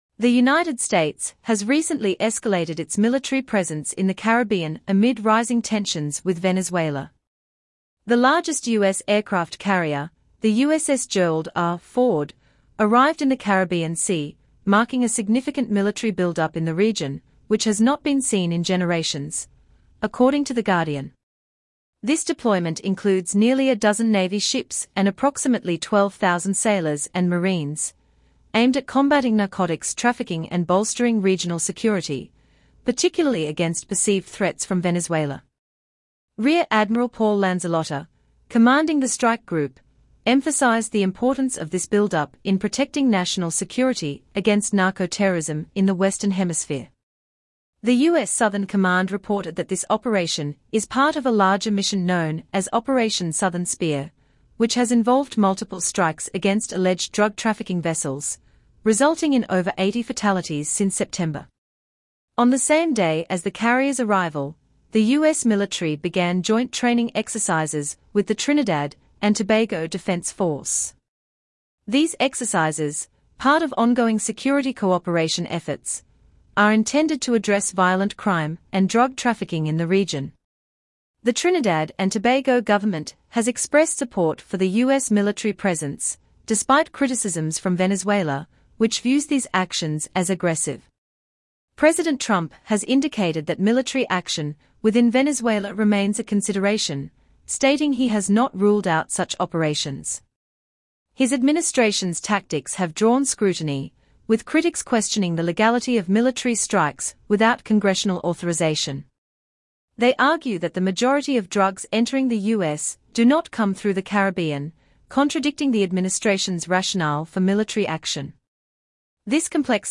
U.S. Military Actions in Caribbean Amid Venezuela Tensions - Daily Audio News Transcript